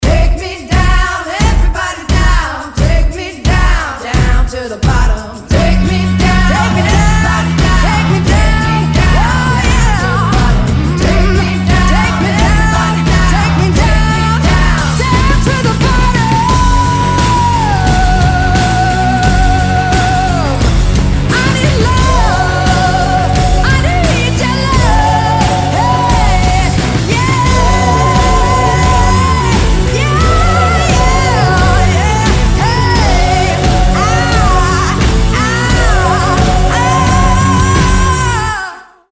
• Качество: 320, Stereo
громкие
женский вокал
Hard rock